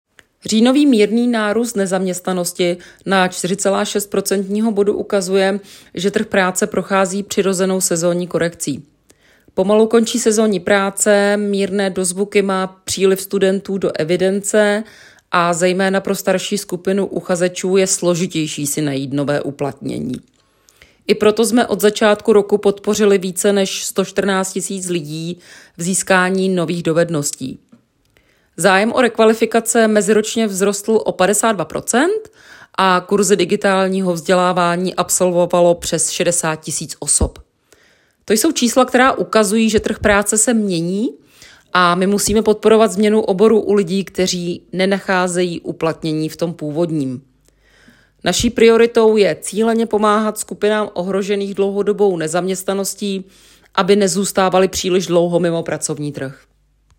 Komentář